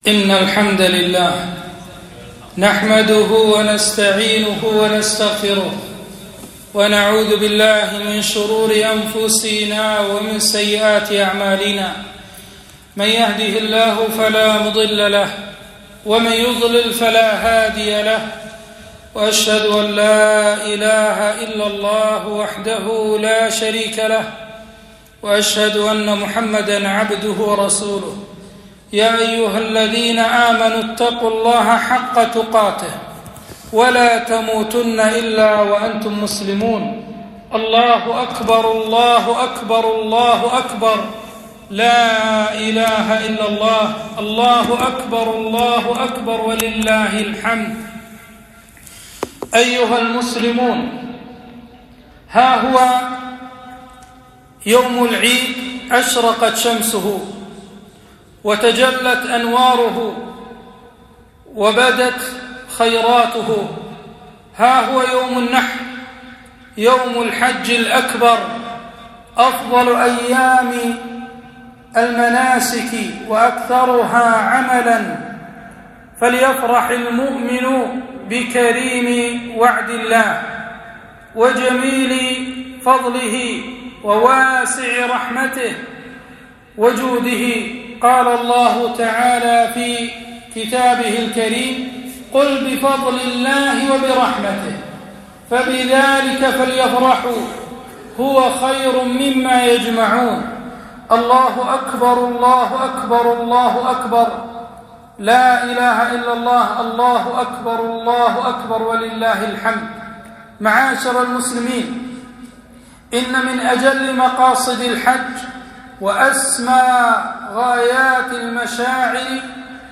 خطبة عيد الأضحى - الحج الأكبر